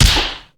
slapstickPunch.ogg